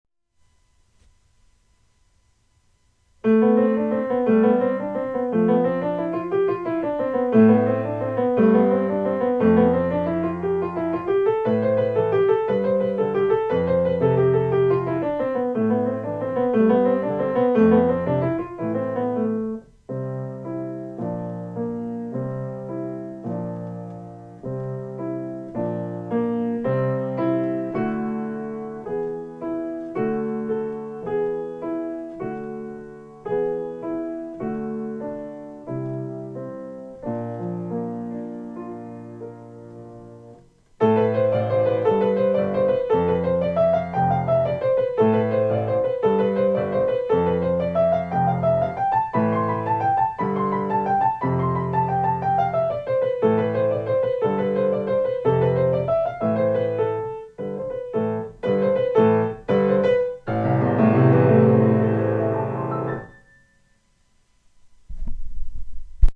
a fast and exciting intermediate piece in A minor.